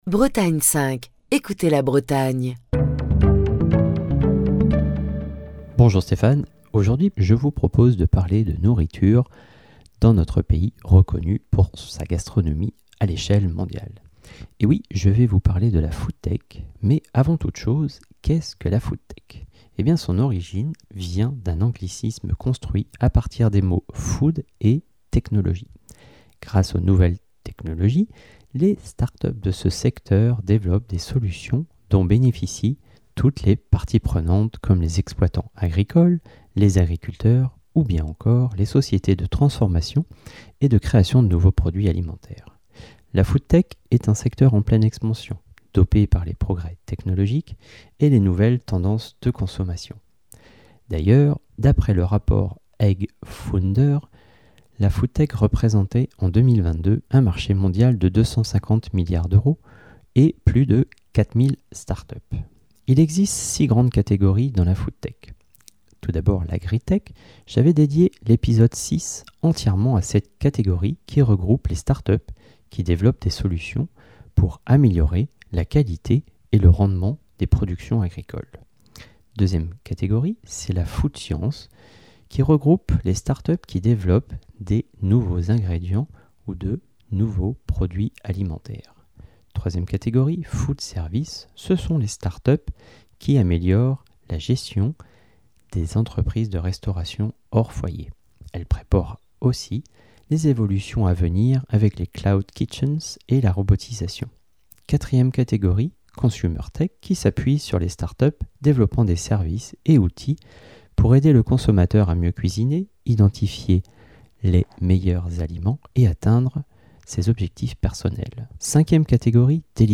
Chronique du 3 juillet 2024.